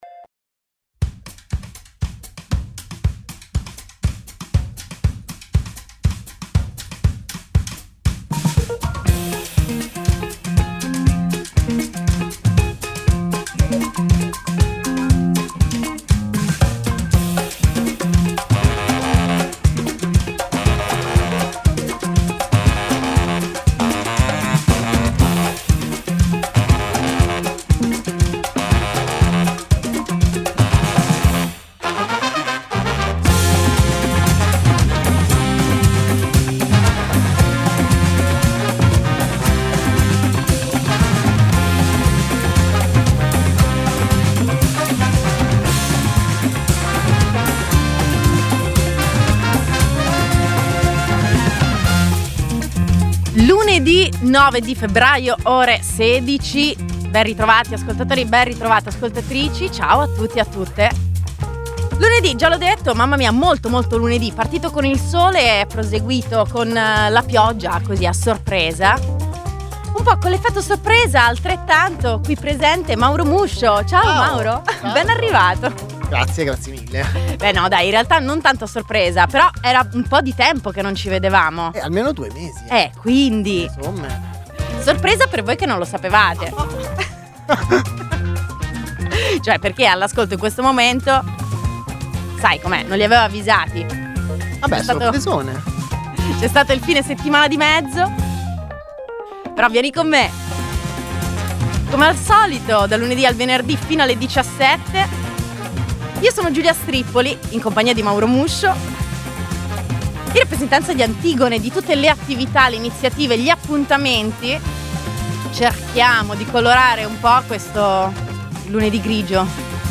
Uno spazio radiofonico per incontrarsi nella vita.